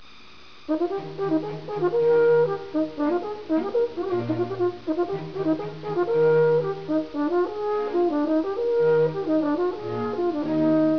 interpretato dal corno di